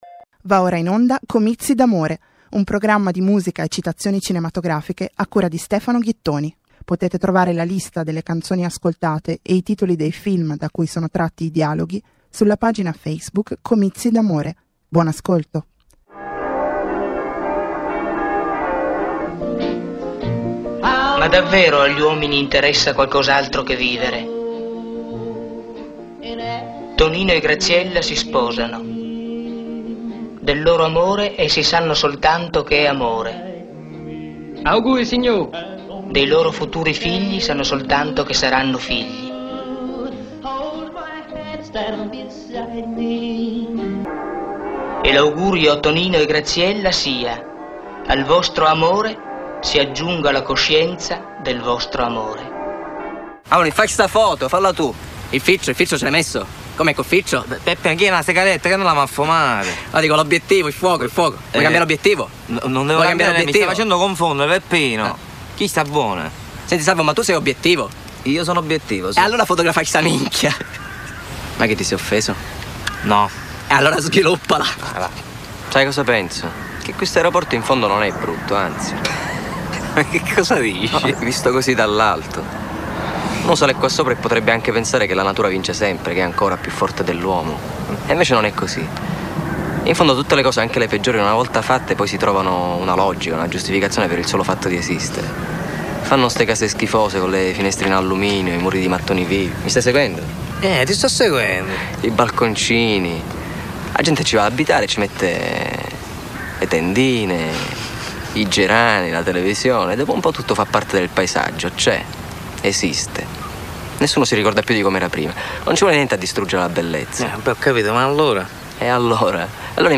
Quaranta minuti di musica e dialoghi cinematografici trasposti, isolati, destrutturati per creare nuove forme emotive di ascolto.